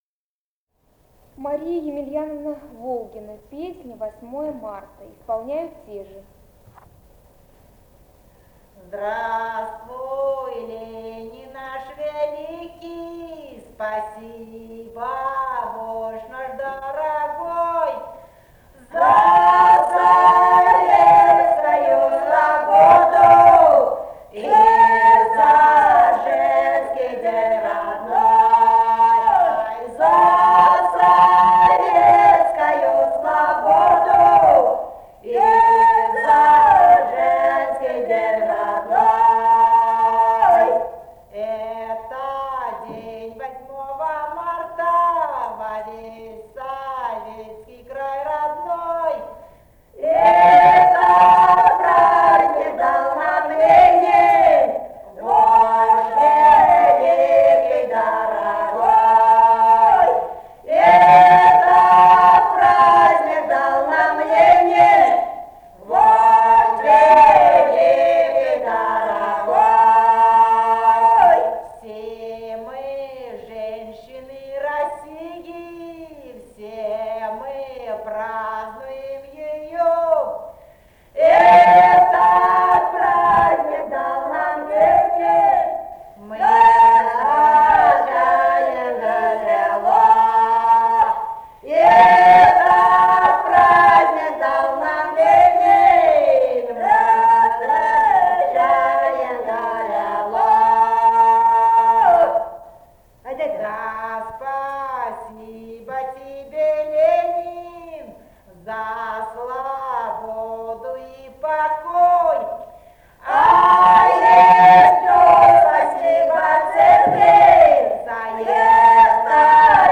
Этномузыкологические исследования и полевые материалы
Самарская область, с. Виловатое Богатовского района, 1972 г. И1316-26